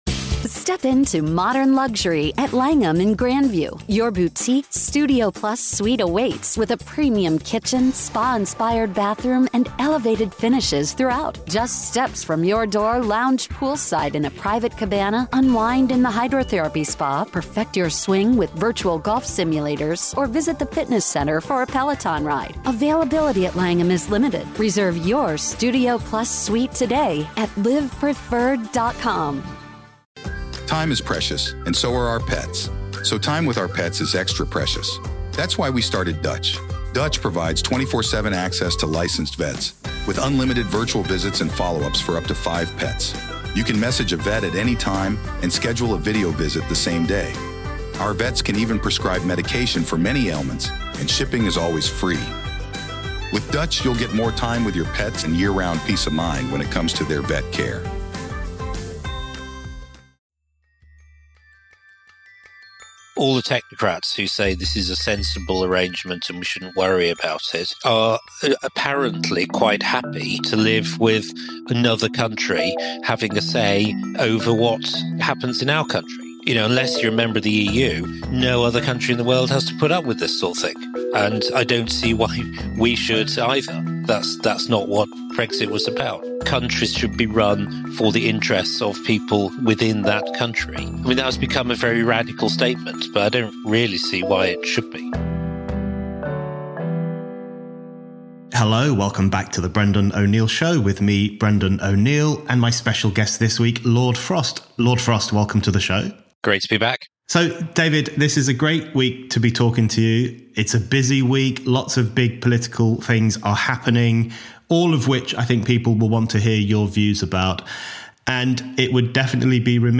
David Frost, former chief Brexit negotiator, and Brendan O'Neill discuss Rishi Sunak’s Northern Ireland deal, the Lockdown Files, the Wakefield blasphemy scandal, and the crucial importance of freedom.